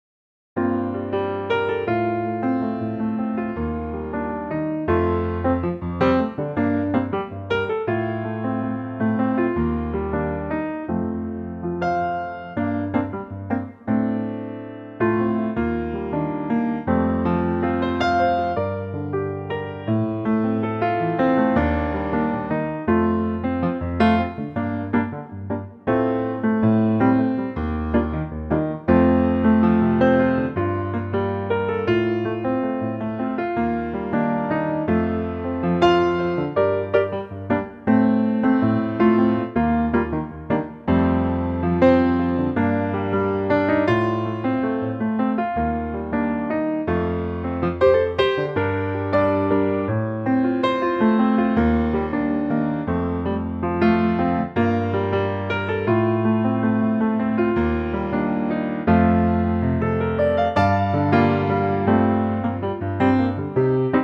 Unique Backing Tracks
key Gm
4 bar intro and vocal in at 11 seconds
key - Gm - vocal range - Bb to Bb